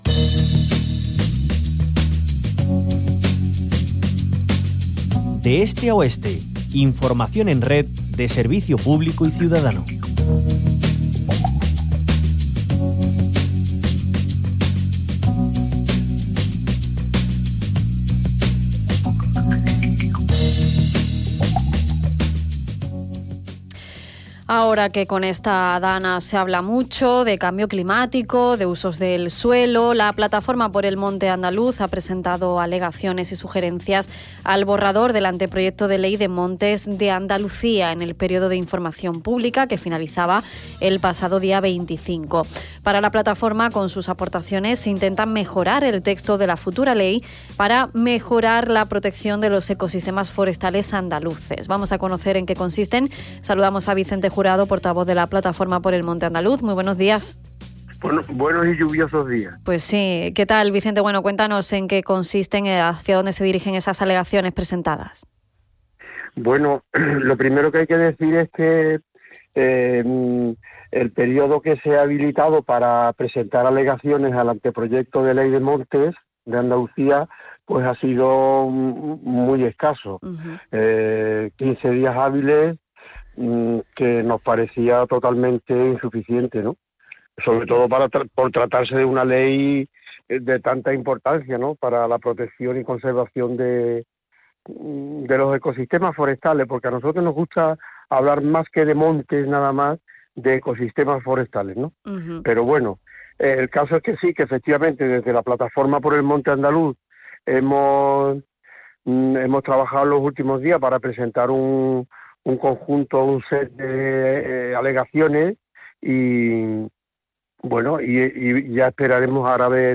Para la Plataforma, con sus aportaciones intentan mejorar el texto de la futura ley en aras de una mejor protección de los ecosistemas forestales andaluces a la vez que mejorar la vinculación de los habitantes de las zonas rurales con espacios forestales y así contribuir a la fijación de población rural. Hablamos con